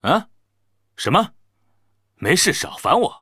文件 文件历史 文件用途 全域文件用途 Kagon_tk_01.ogg （Ogg Vorbis声音文件，长度3.2秒，98 kbps，文件大小：39 KB） 源地址:地下城与勇士游戏语音 文件历史 点击某个日期/时间查看对应时刻的文件。 日期/时间 缩略图 大小 用户 备注 当前 2018年5月13日 (日) 02:15 3.2秒 （39 KB） 地下城与勇士  （ 留言 | 贡献 ） 分类:卡坤 分类:地下城与勇士 源地址:地下城与勇士游戏语音 您不可以覆盖此文件。